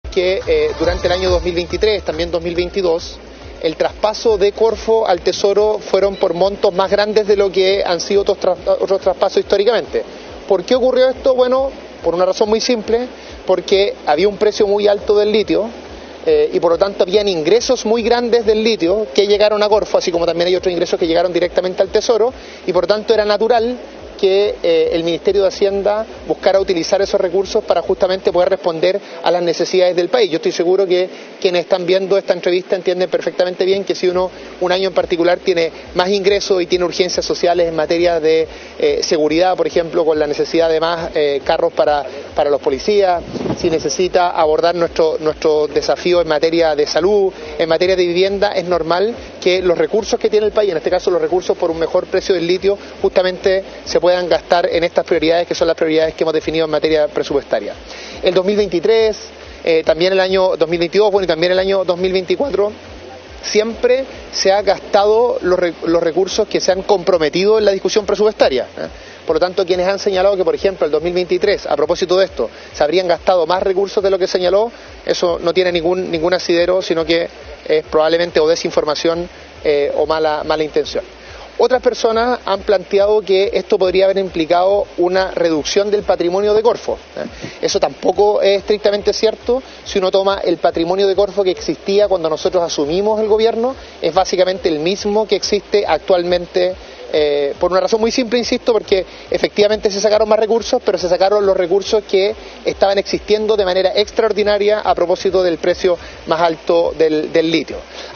En un punto de prensa desde el Palacio de La Moneda, el secretario de Estado aseguró que estas operaciones han sido “totalmente regulares y transparentes” y que la controversia es “bastante artificial”.